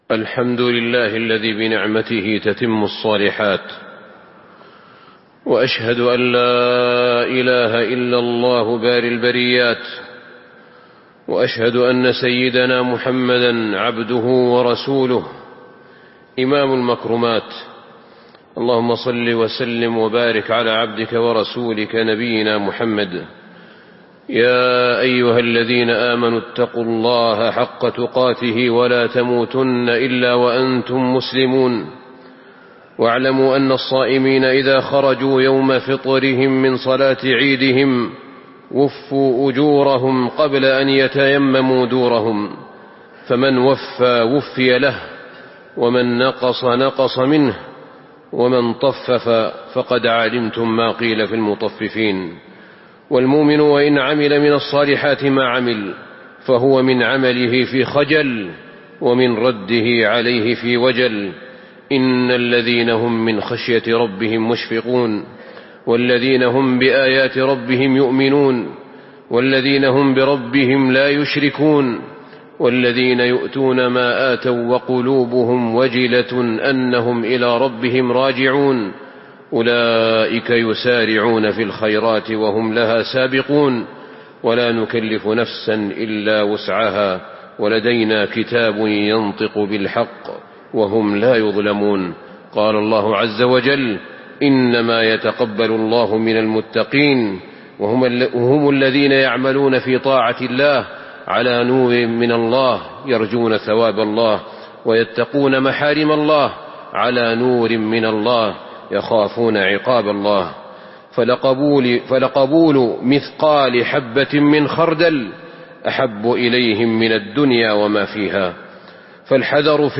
تاريخ النشر ٣ شوال ١٤٤٥ هـ المكان: المسجد النبوي الشيخ: فضيلة الشيخ أحمد بن طالب بن حميد فضيلة الشيخ أحمد بن طالب بن حميد رجاء المتقين والمذنبين في عفو رب العالمين